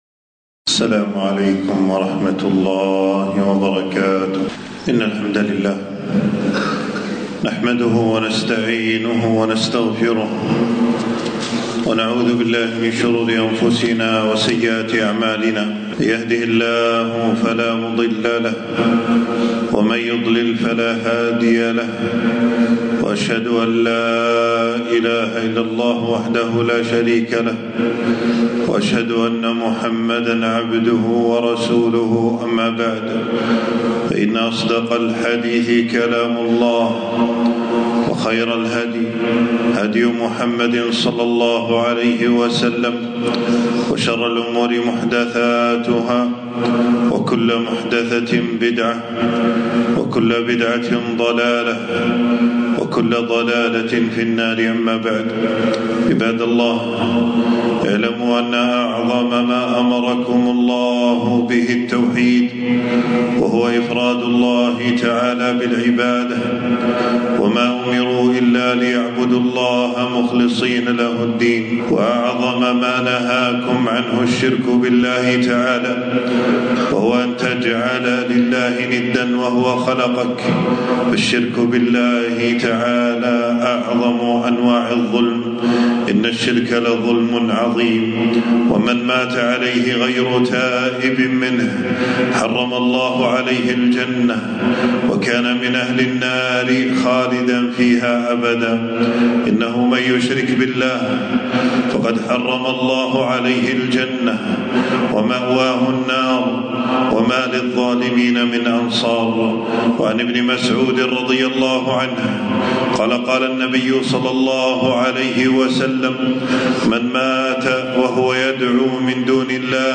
خطبة - الشرك وخطورته وبعض مظاهره